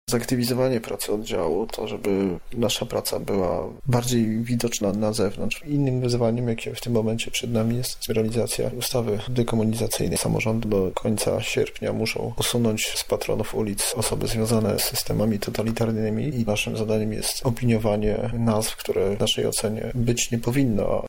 O swoich nowych zadaniach mówi sam dyrektor: